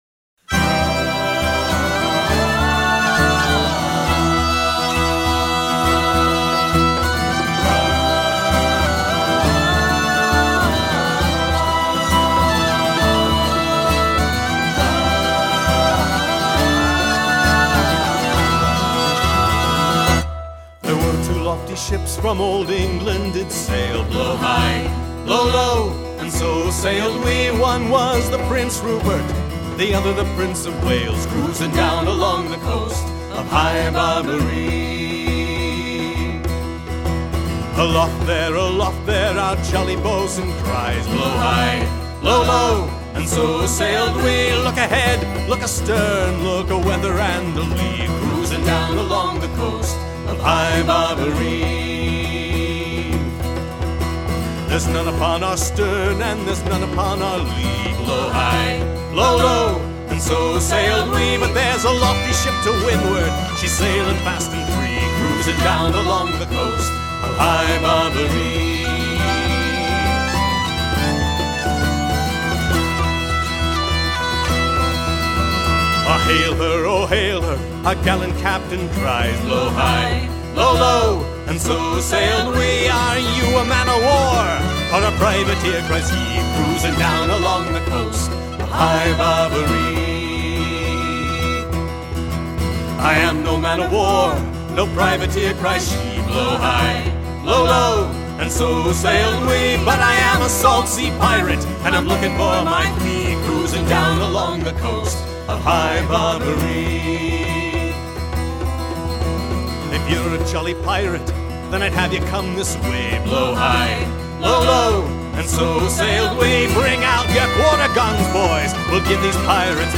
High Barbaree probably began as a ballad that was sung aboard ships as a forebitter and was eventually pressed into service as a capstan shanty as well. It's a rousing tale of piracy along the coast of North Africa, resulting in a sea battle and revenge.